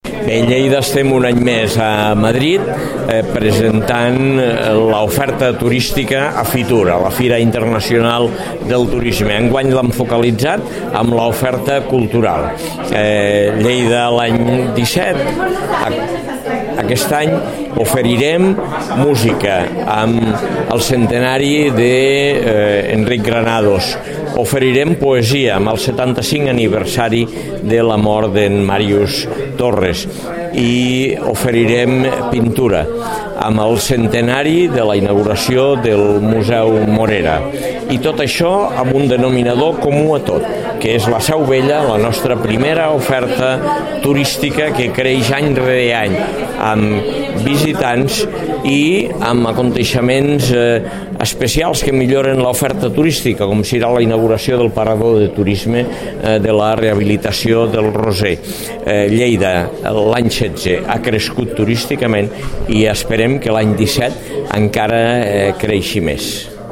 tall-de-veu-de-lalcalde-de-lleida-angel-ros